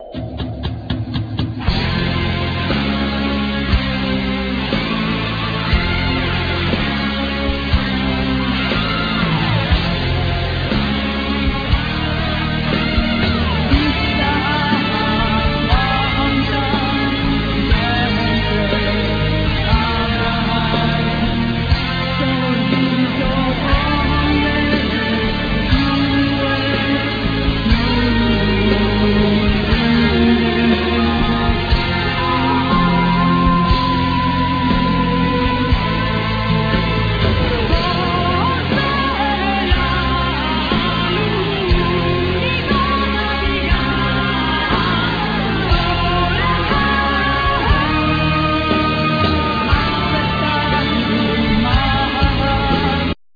Keyboard,Orchestrations
Vocals
Drum,Programming